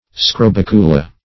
Search Result for " scrobicula" : The Collaborative International Dictionary of English v.0.48: Scrobicula \Scro*bic"u*la\, n.; pl.